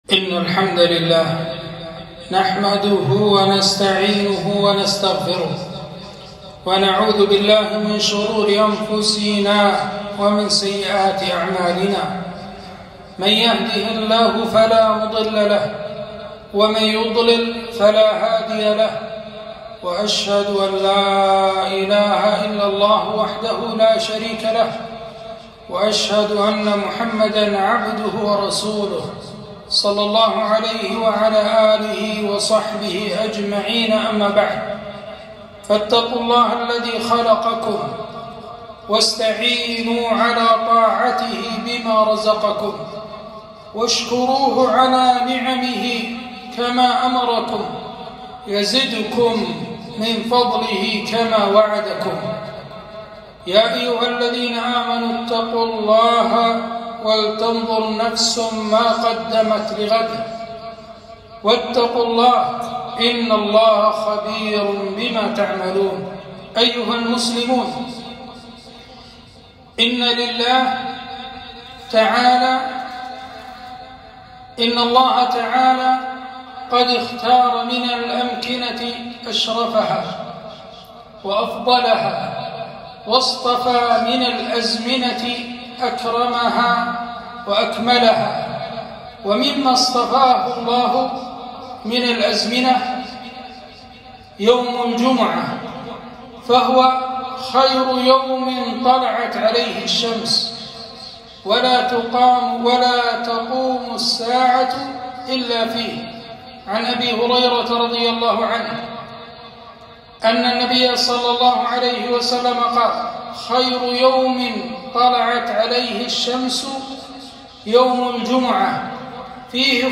خطبة - سنن وآداب يوم الجمعة